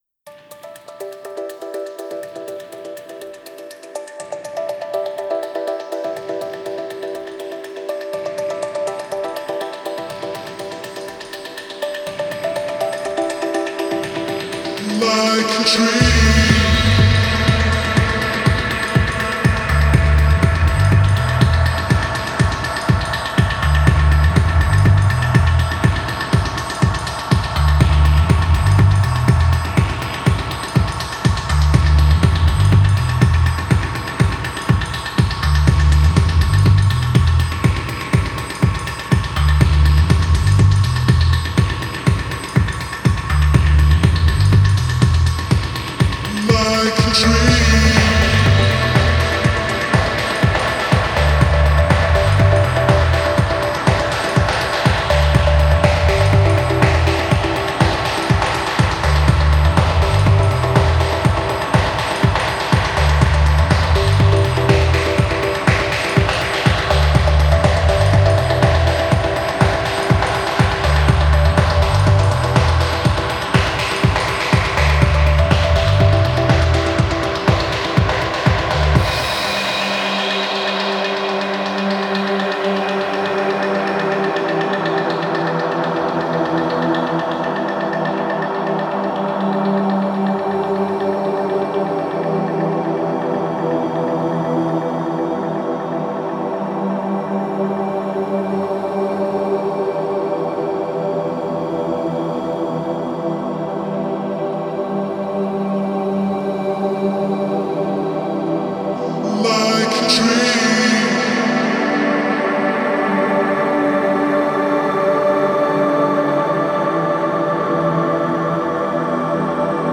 d'une pop planante flirtant avec l'electro qui s'étire